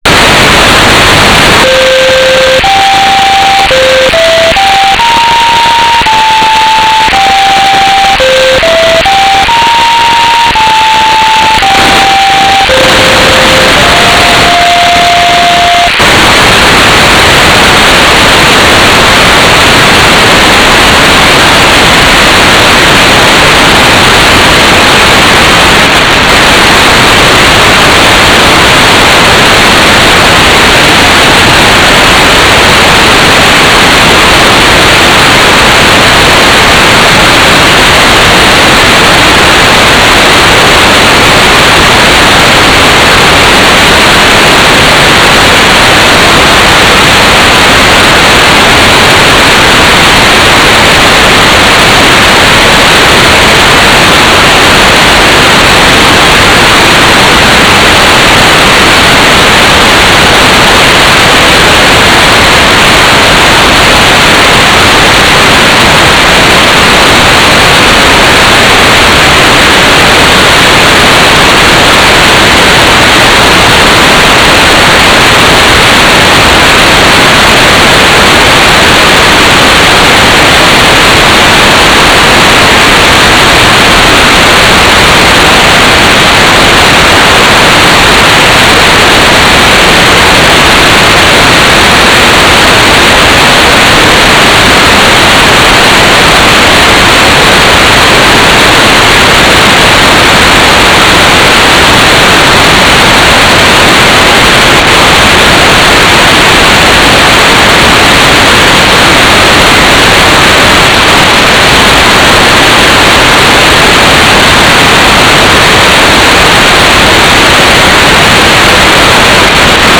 "transmitter_description": "Mode U - GFSK4k8 - AX.25 - Telemetry",